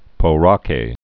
(pō-räkā)